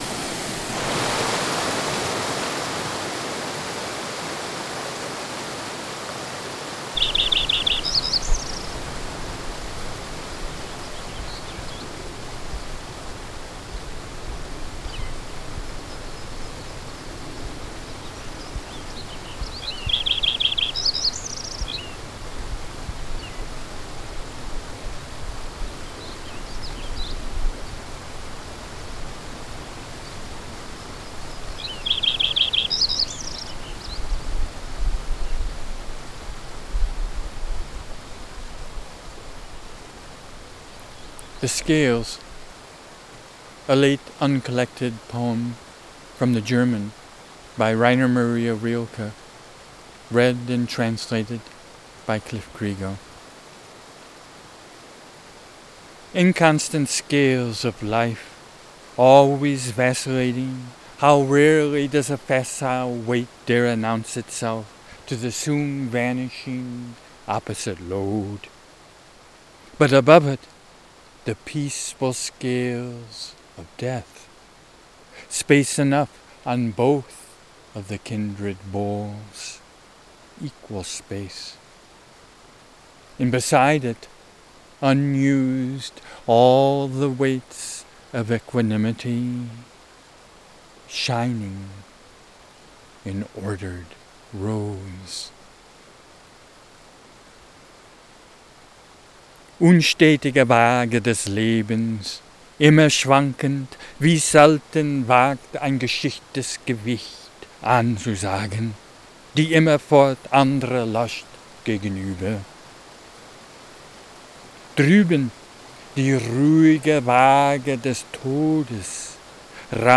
recorded in the Eagle Cap Wilderness|